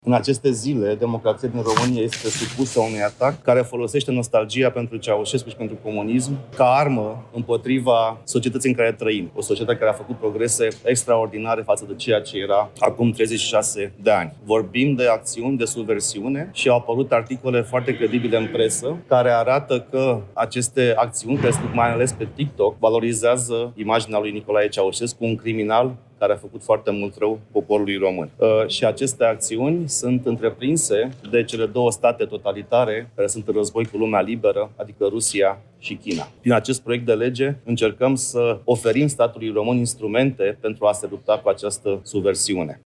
Cristian Ghinea, senator USR: „Prin acest proiect de lege încercăm să oferim statului român instrumente pentru a se lupta cu această subversiune”